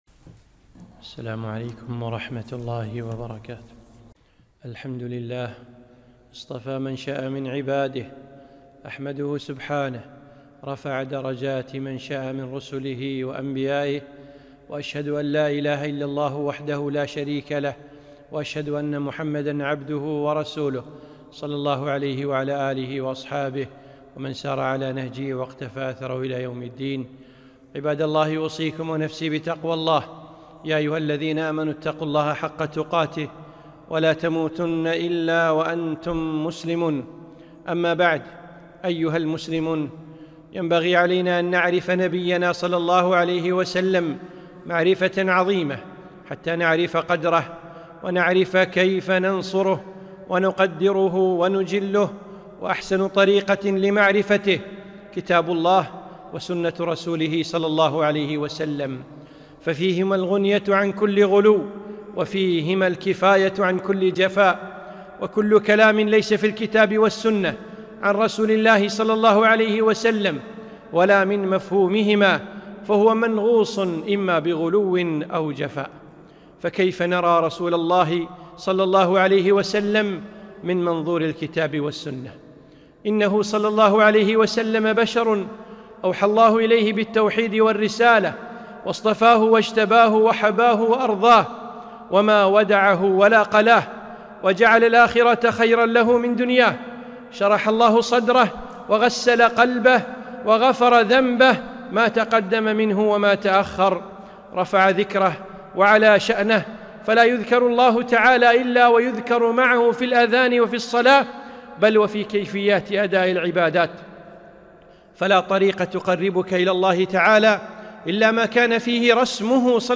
خطبة - ‌كيف نرى رسول الله ﷺ